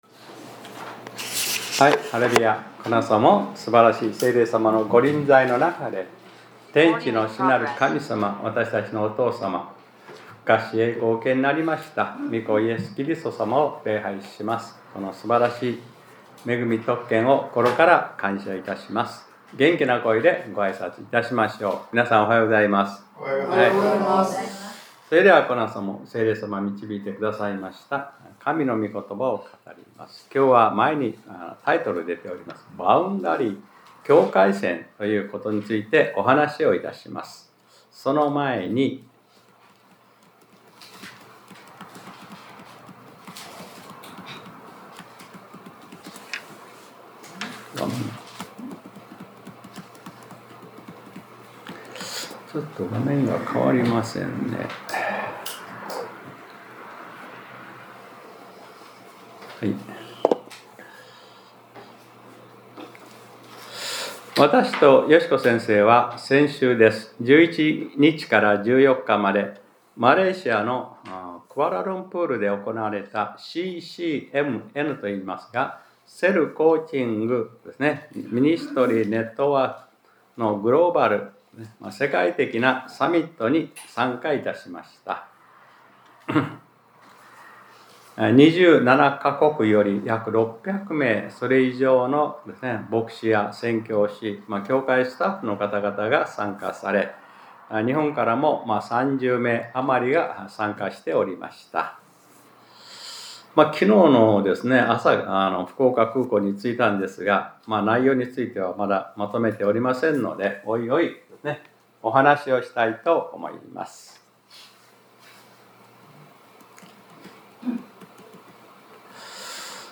2025年11月16日（日）礼拝説教『 バウンダリー（境界線 』